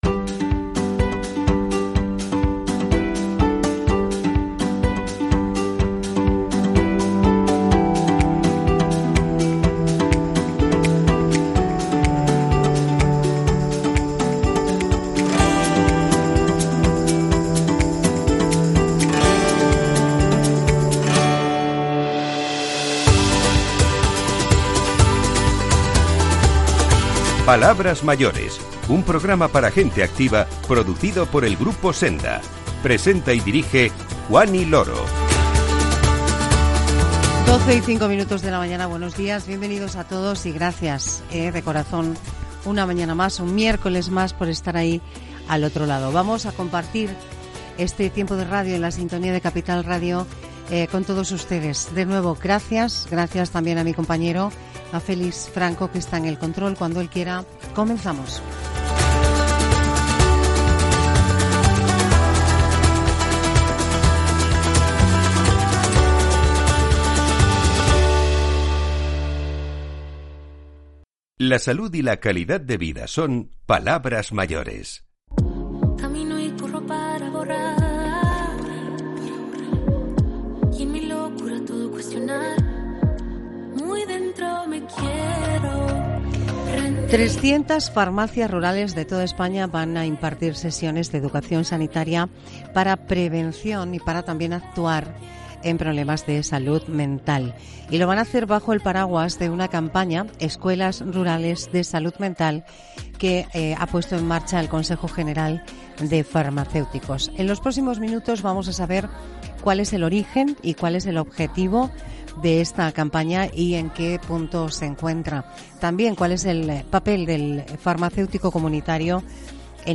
Para ello nos paramos en el año 1991 y, desde ahí, con sonidos de ese año, recordamos qué pasaba en nuestro país y en el mundo, qué veíamos en la televisión y quién lideraba los diferentes deportes.